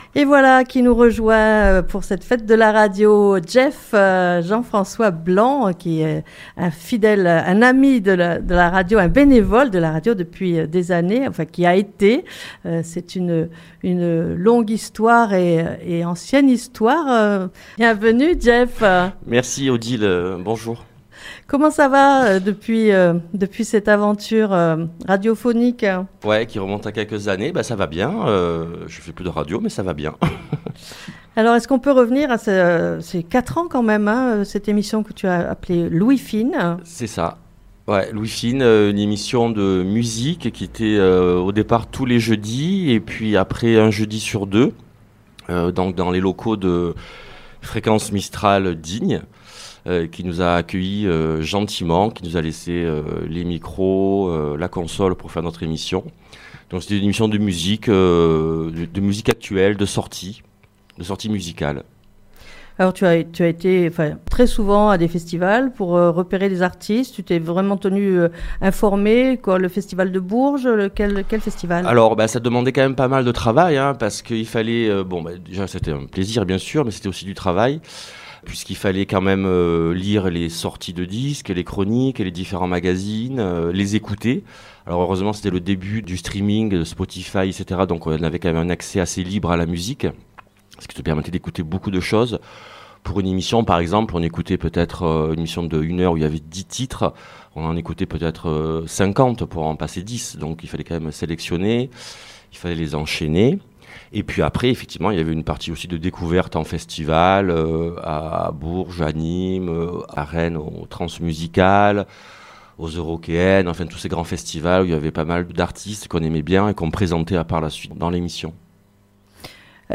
Vendredi 6 Juin 2025 Dans le cadre de la fête de la radio et de la journée portes ouvertes du studio de Fréquence Mistral Digne le 6 juin 2025, plusieurs bénévoles animateurs-trices actuels.les ont pu se rencontrer, et découvrir chacun.e le contenu de leurs émissions et leur investissement et expérience de la radio.